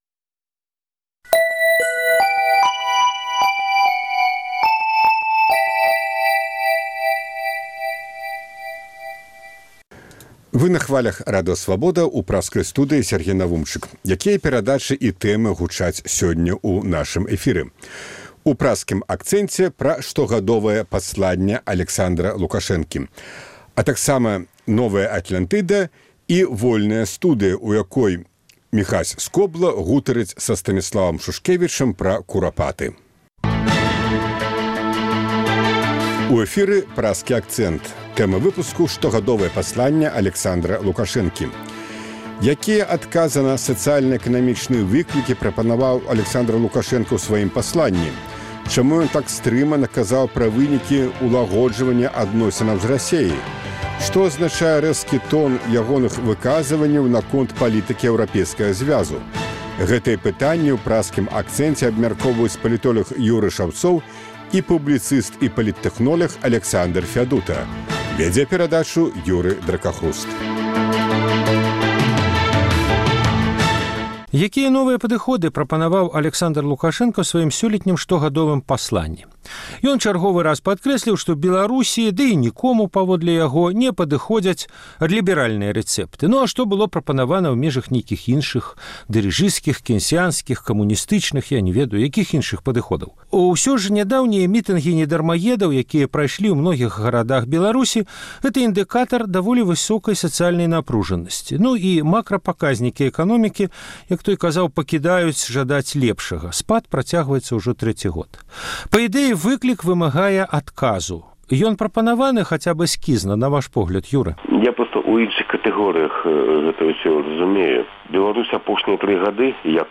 палітоляг
публіцыст і паліттэхноляг